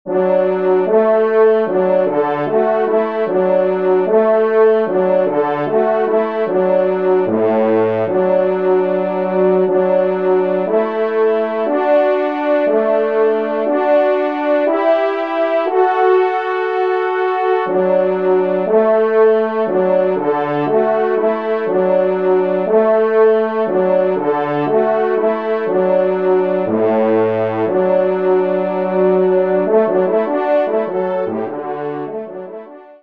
Genre :  Divertissement pour Trompe ou Cor
2e Trompe